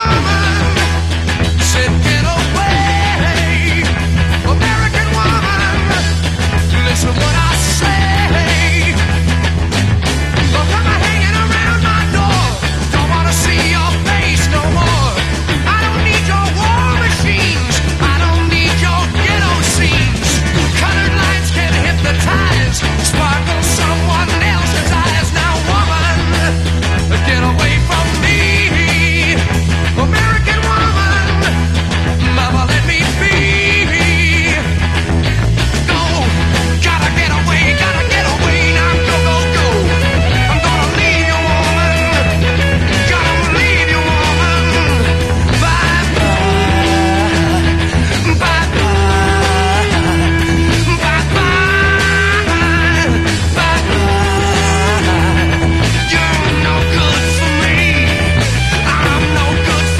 Using a Zoom R20 multi track recorder and laying down some new material. The 2 guitars in this clip were used in the recording and it's almost finished.